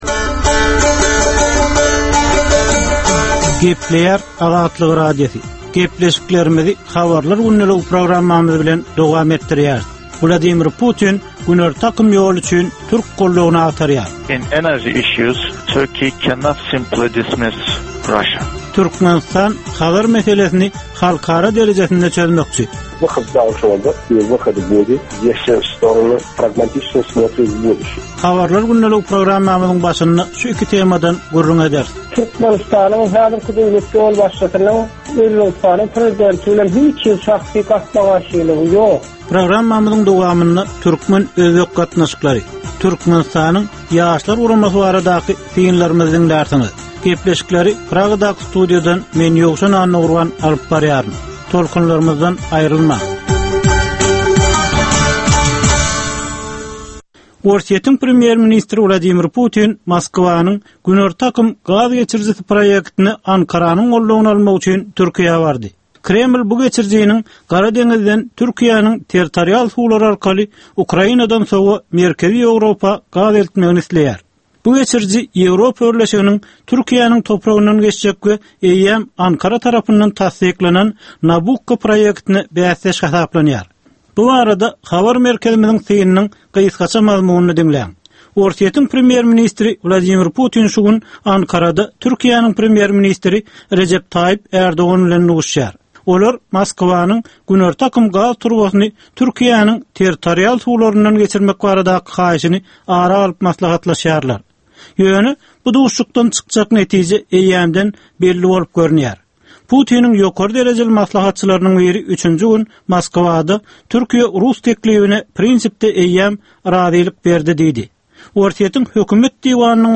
Türkmenistandaky we halkara arenasyndaky sonky möhüm wakalar we meseleler barada ýörite informasion-analitiki programma. Bu programmada sonky möhüm wakalar we meseleler barada ginisleýin maglumatlar, analizler, synlar, makalalar, söhbetdeslikler, reportažlar, kommentariýalar we diskussiýalar berilýär.